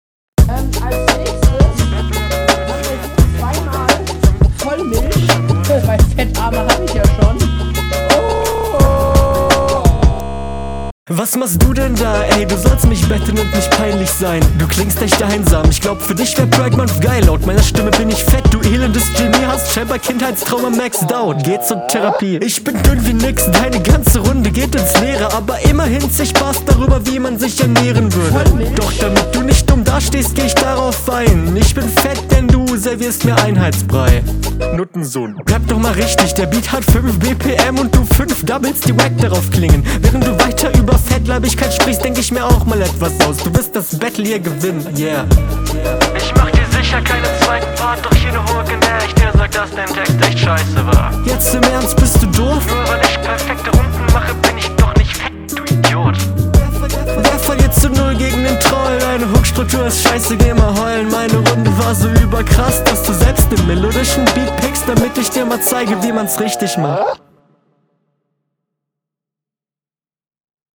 mach mal s-laute aus (dein gegner hätte ein wortspiel dazu) sich selbst troll zu nennen …